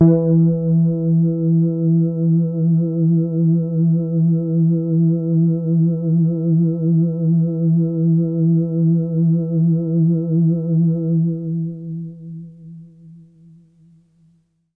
低通滤波器使声音变得圆润而柔和。较低的键可以作为低音的声音，而较高的键可以作为柔和的主音或垫音。在较高的区域，声音变得非常柔和，在归一化之后，一些噪音变得很明显。我决定不使用降噪插件来消除这些噪音，而是让它保持原样。用Waldorf Q Rack合成器创作，通过我的Spirit 328数字控制台在Cubase 4中以32位波形文件进行数字录制。
Tag: 低音 电子 醇厚 多样品 柔软 合成器 华尔